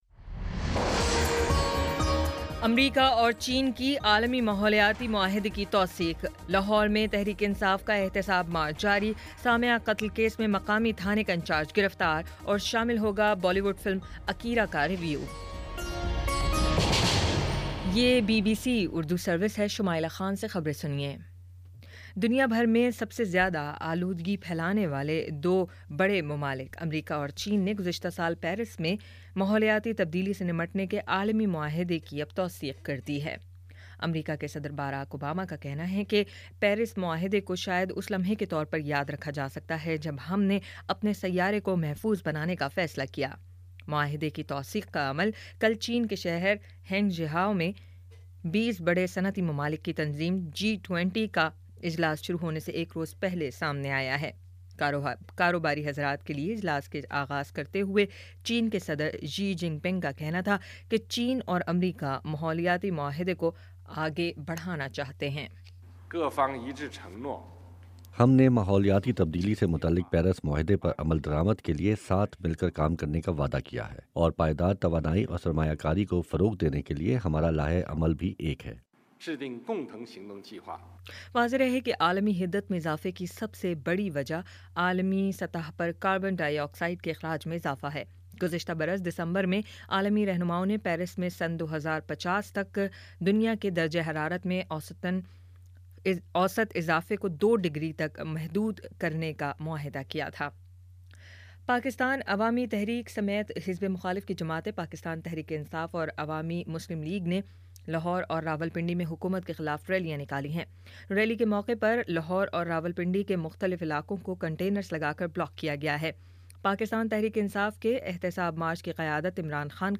ستمبر 03 : شام پانچ بجے کا نیوز بُلیٹن